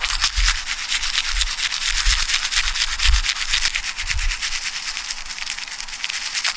《歯磨き》フリー効果音
歯磨きをしている効果音。シャコシャコシャコ。